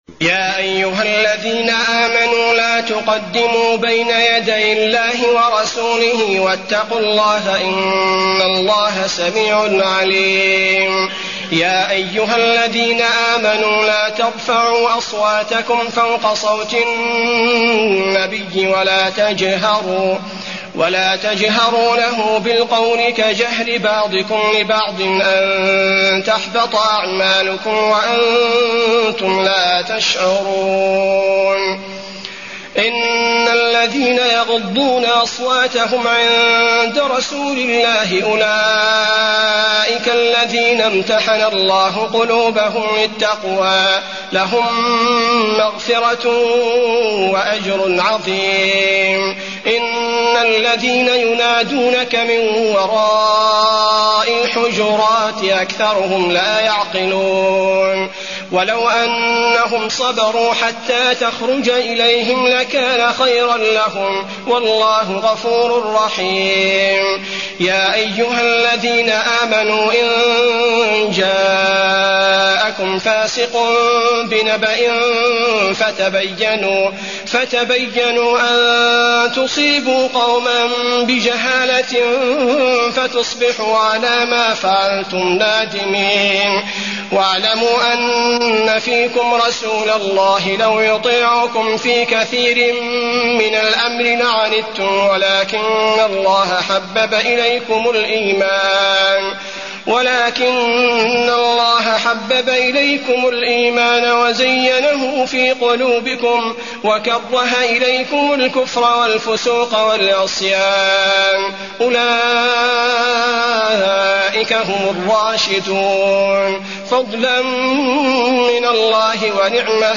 المكان: المسجد النبوي الحجرات